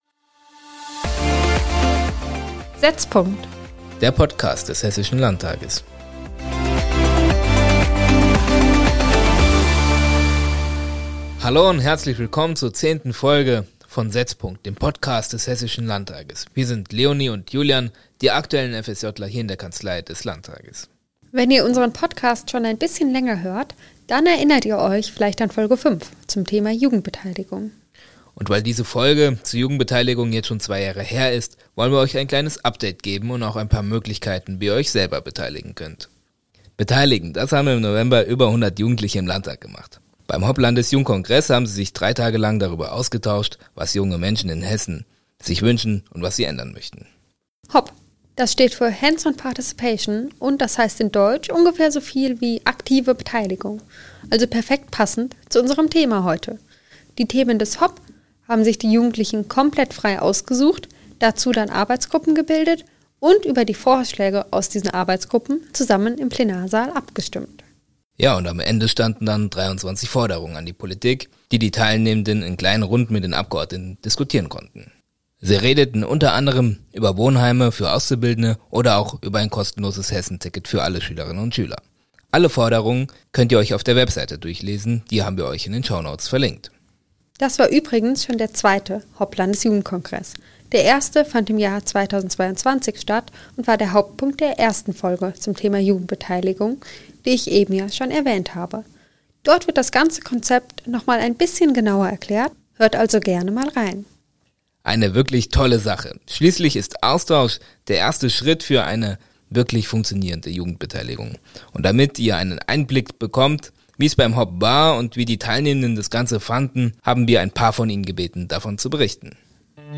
Außerdem sprechen die beiden FSJ-ler mit der SPD-Abgeordneten Cirsten Kunz-Strueder.